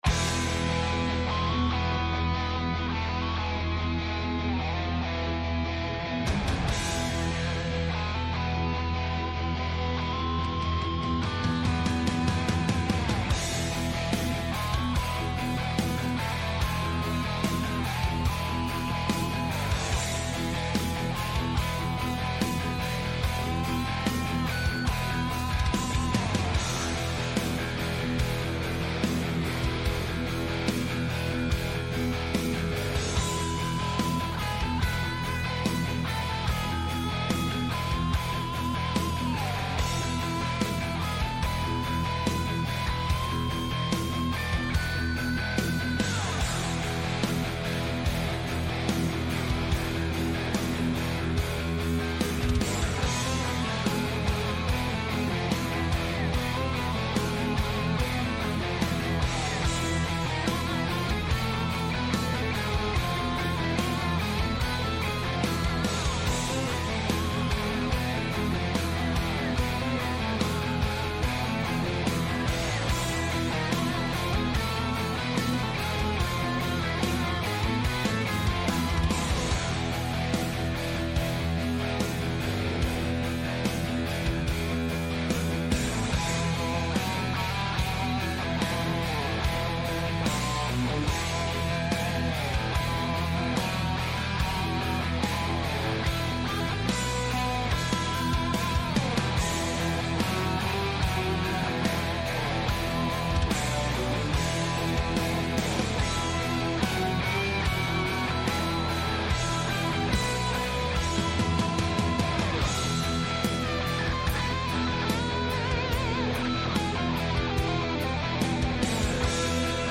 Επιτρέπεται μάλιστα η είσοδος στο στούντιο σε κάθε λογής περαστικούς!